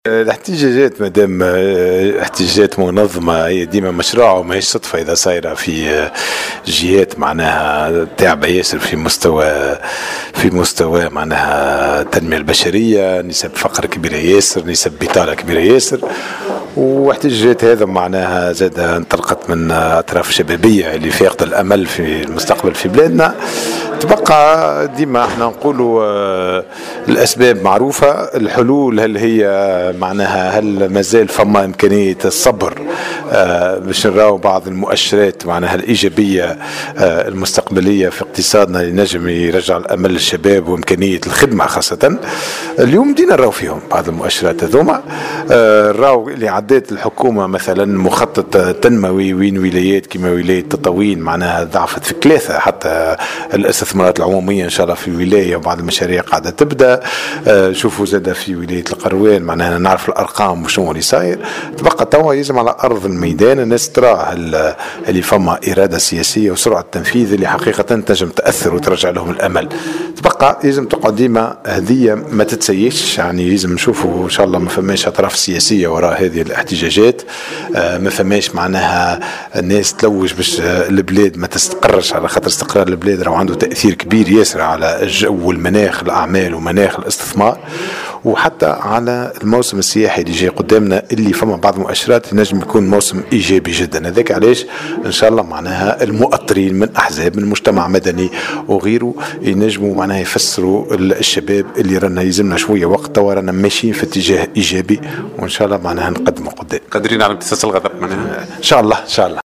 وعبر عن أمله في تصريحات لمراسل "الجوهرة أف أم" على هامش لقاء في صفاقس، بأن لا يكون هناك أطرافا سياسية تسعى إلى توظيف هذه الاحتجاجات وبالقدرة على "امتصاص الغضب".